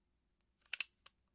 噪音和合成物" 枪支组装
描述：我记录了我穿上枪托，插入弹夹和扣动我的MP5.
标签： 装配 公鸡 斗鸡 效果 噪音 声音
声道立体声